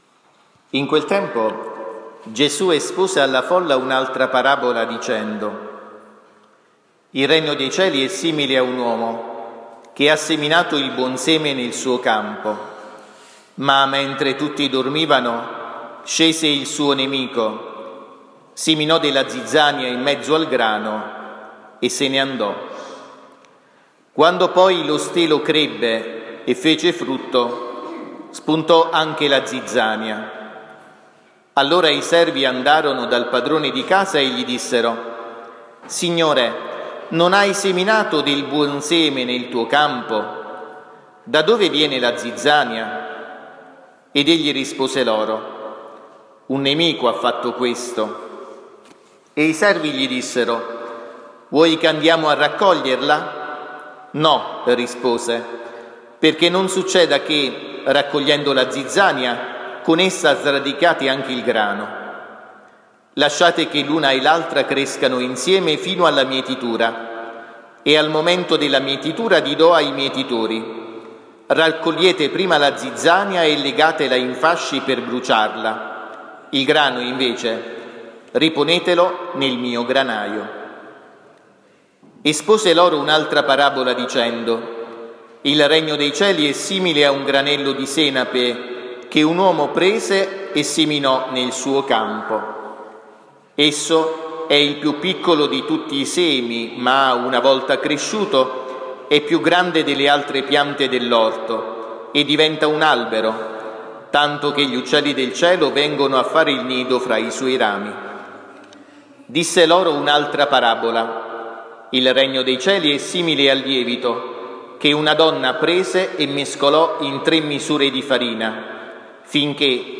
XVI DOMENICA DEL TEMPO ORDINARIO (ANNO A) – 23 luglio 2023